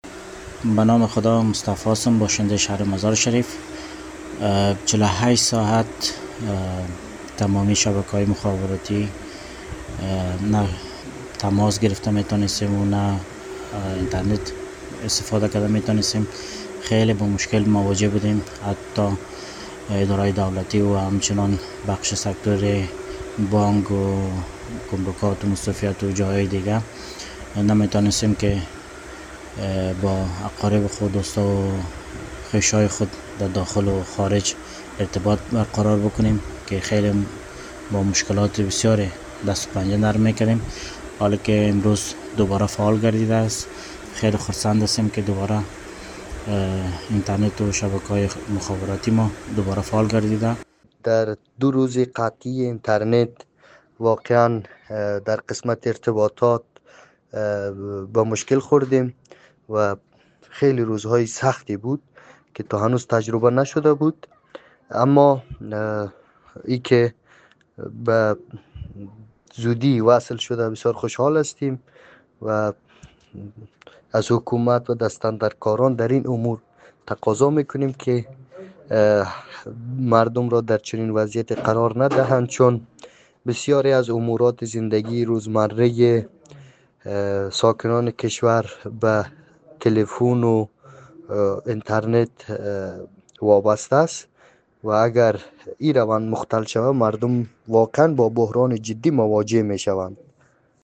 برخی از شهروندان بلخی در گفت وگو با رادیو دری می‌گویند در دو شبانه روز گذشته شرایط دشواری را تجربه کردند و امیدوارند که دوباره شاهد قطع اینترنت در افغانستا...